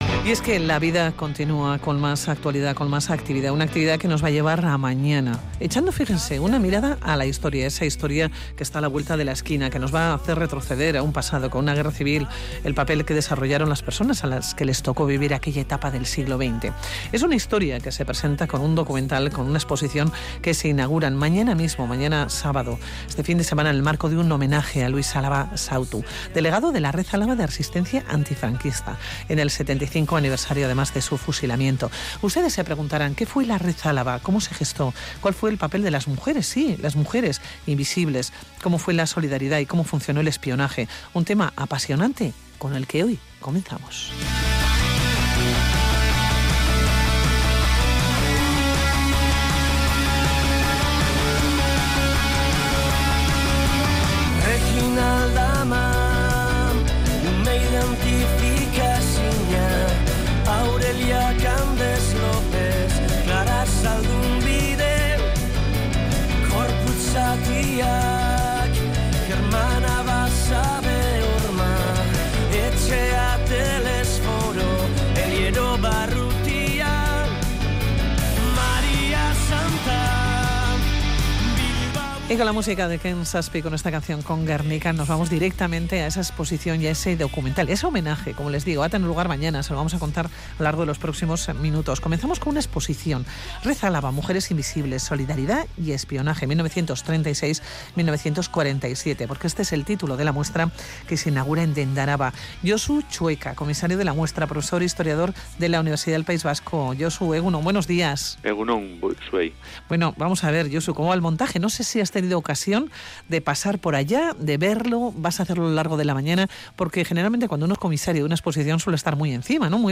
Audio: Es un homenaje a Luis Álava, Sautu, y al trabajo que desarrollaron muchas personas que colaboraron con él en los años de la Guerra Civil y en la posguerra; sobre todo se centra en las mujeres. Entrevista